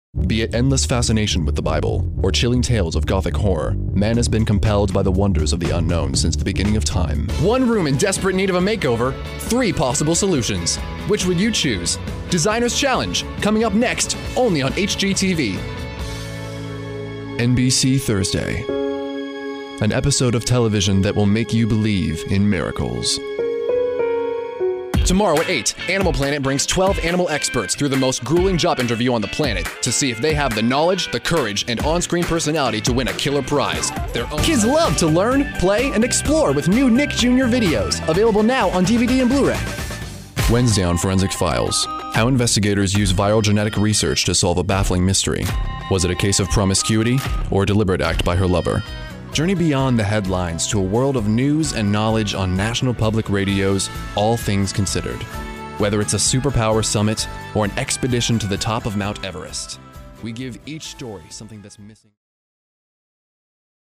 Male
Genuine youthful sound, but with range in more mature sounds as well.
Radio / TV Imaging
Commercials / Internet Videos
Television/Radio Promos
Words that describe my voice are Youthful, Friendly, Conversational.